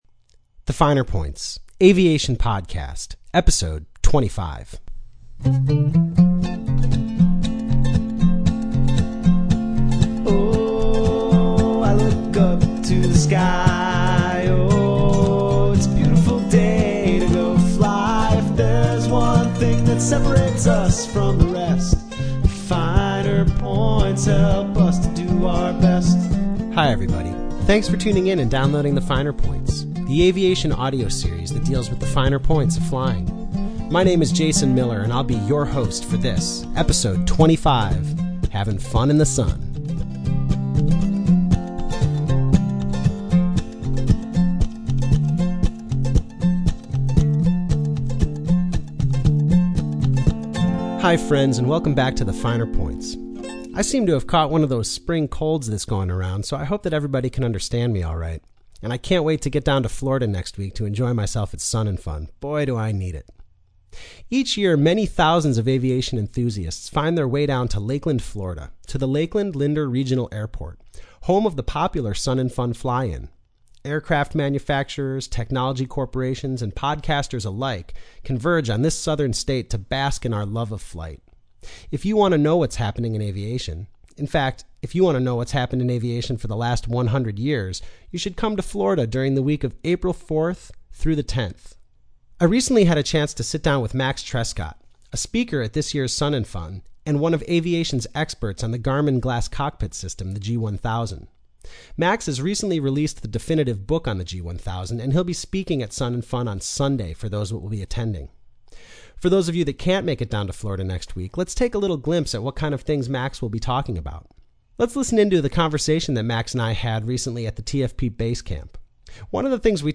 The most recent podcast, released by the Aero News Network on Friday, is an interview they conducted at Sun ‘n Fun a couple of weeks ago.